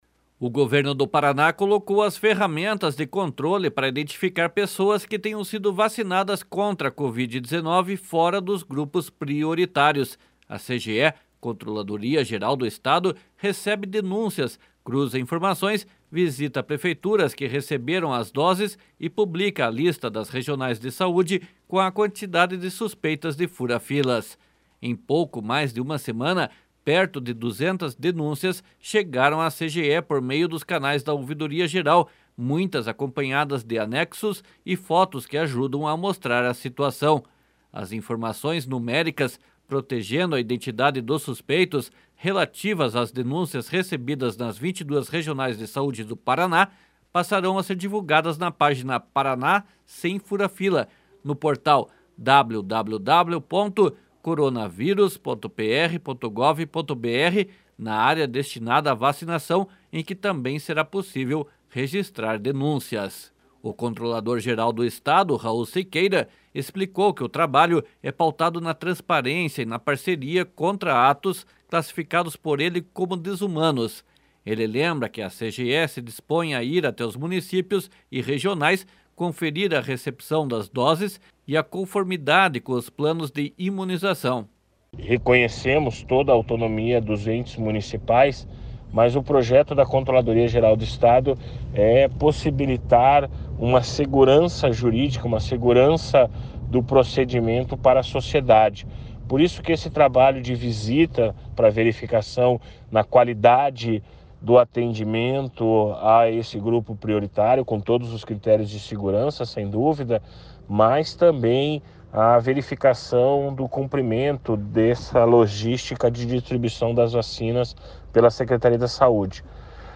//SONORA RAUL SIQUEIRA//